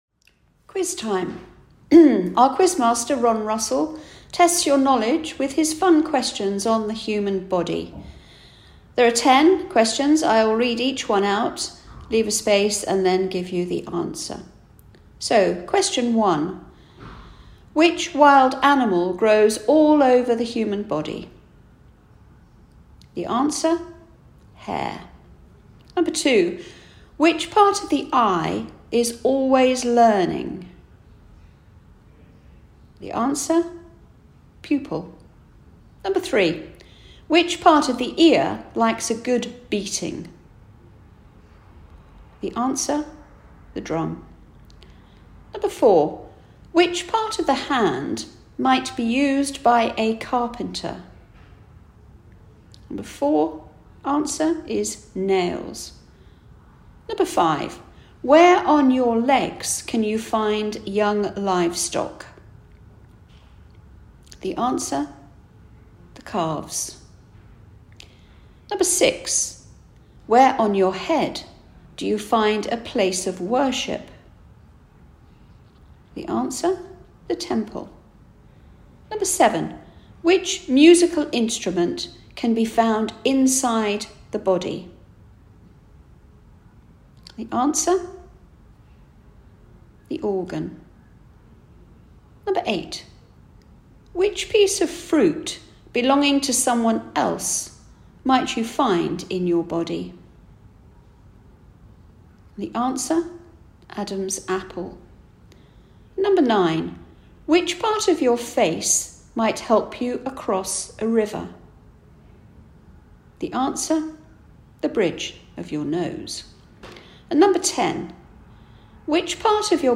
Blind Veterans UK Review September 2022: Quiz and answers